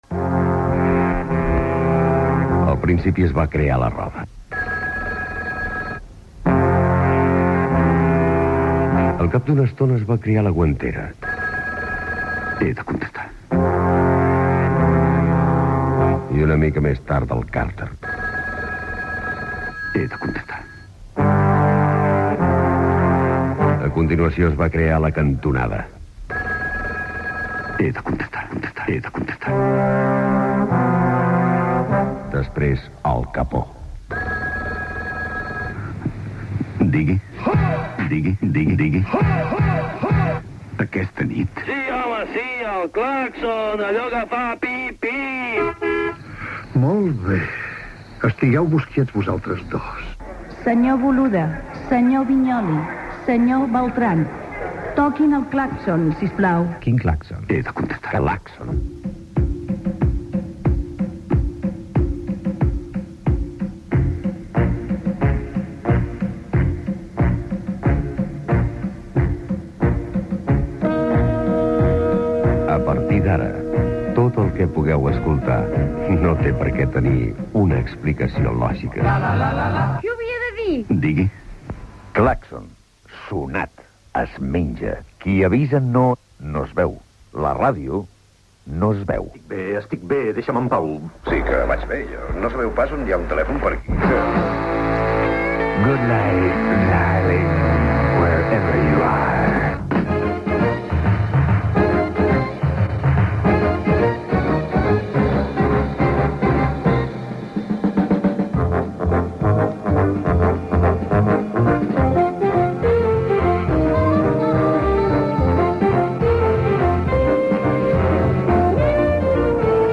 Careta del programa